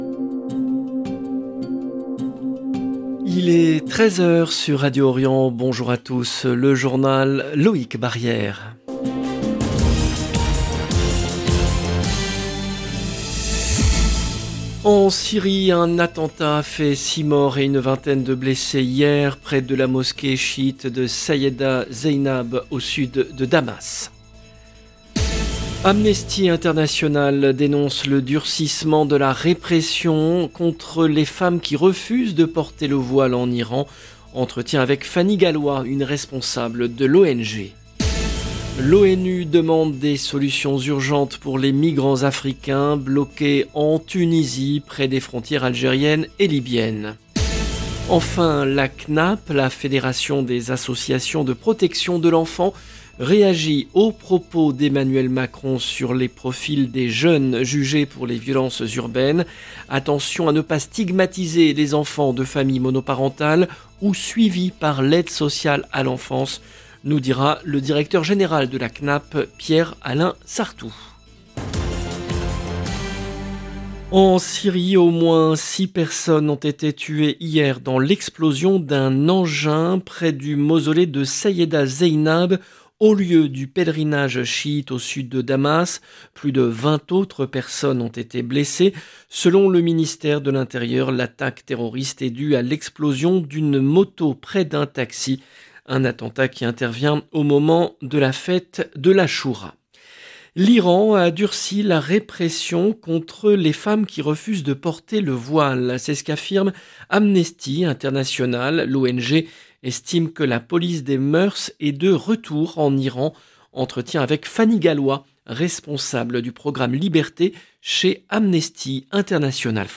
LE JOURNAL EN LANGUE FRANÇAISE DE MIDI DU 28/07/23